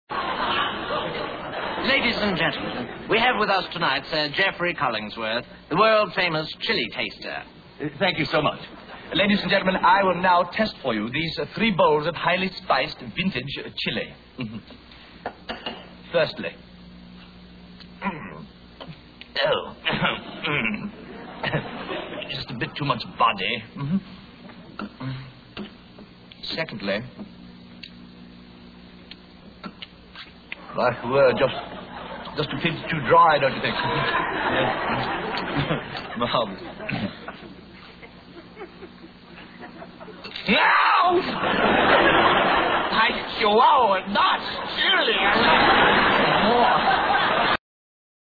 On October 14, 1968, Bobby guest starred on NBC's Rowan & Martin's Laugh-In.
In this small spot, he appeared as a chili taster.